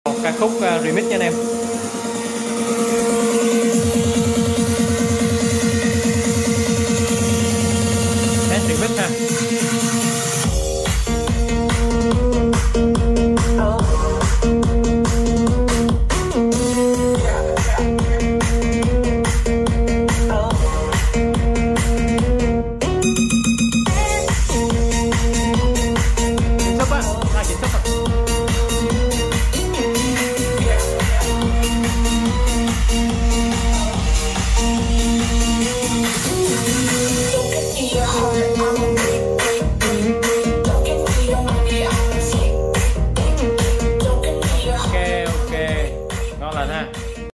Test âm thanh dàn ONKYO